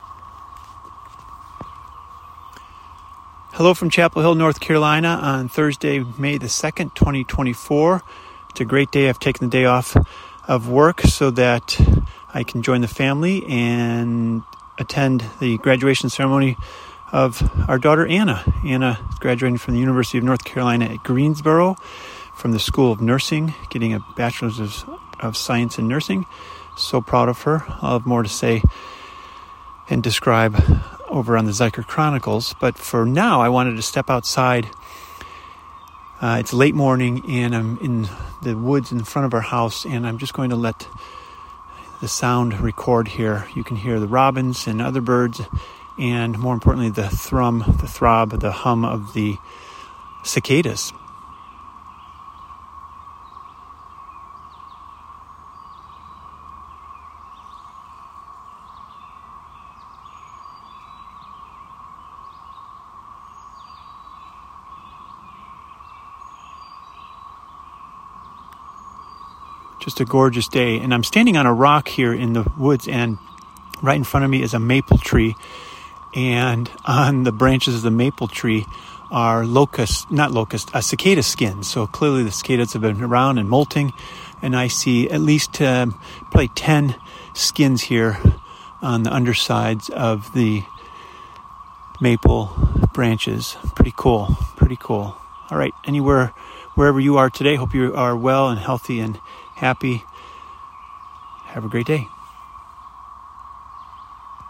Hum of Cicadas
From Chapel Hill, North Carolina, a moment in the woods to listen to the birds and cicadas.